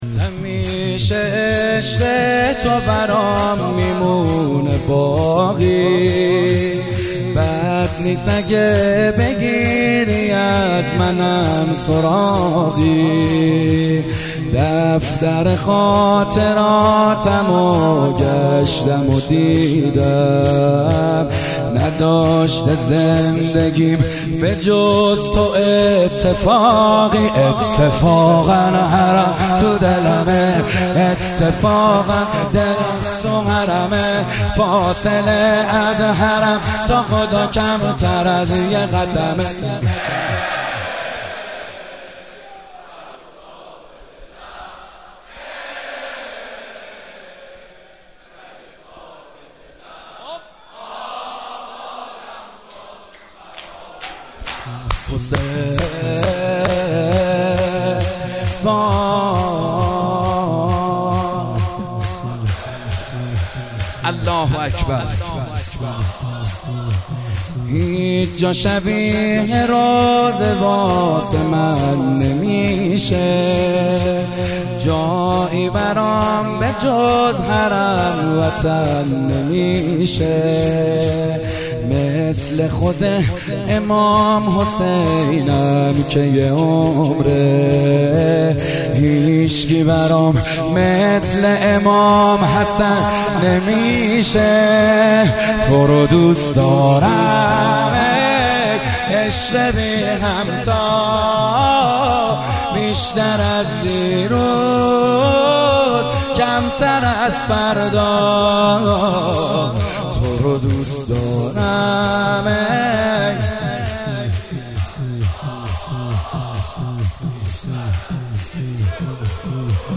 شور تلفیقی